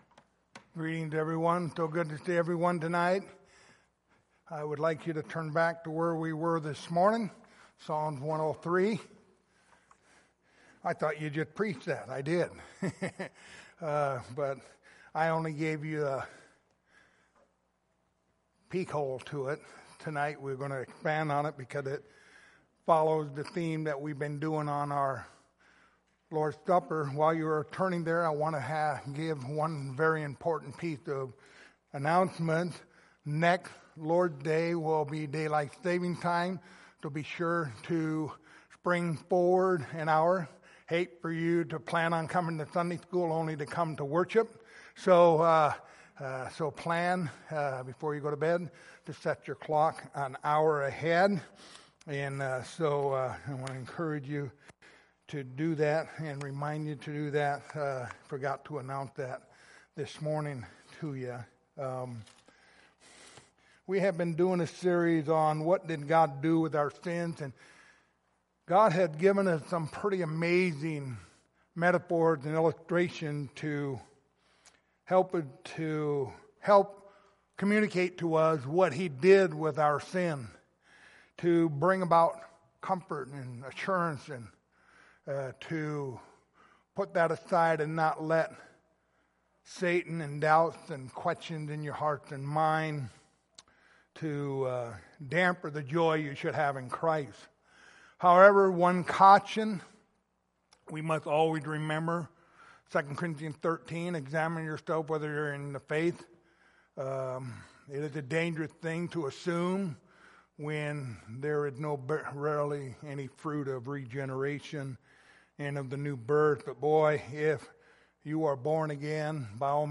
Passage: Psalm 103:1-22 Service Type: Lord's Supper Topics